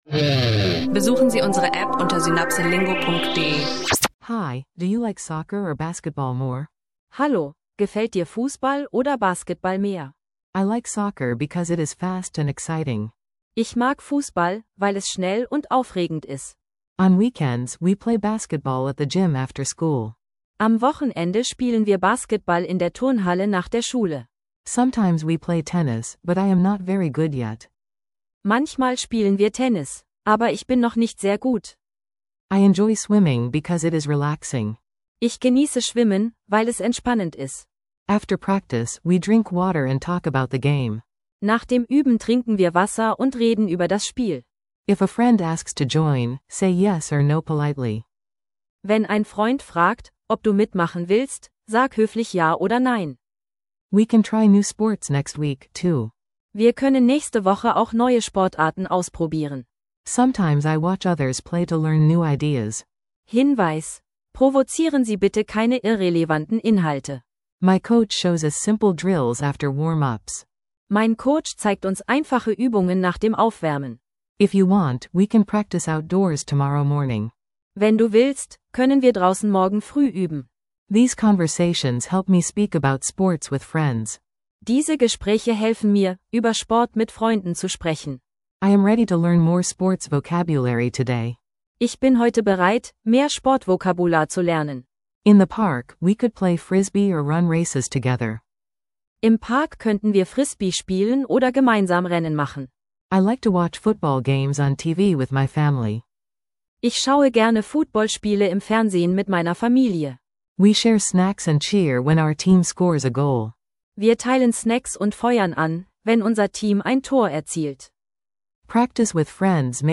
Zweisprachige Dialoge zu Sportarten mit einfachen Übungen – perfekt für Englisch lernen online und unterwegs.